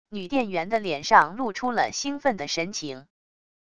女店员的脸上露出了兴奋的神情wav音频生成系统WAV Audio Player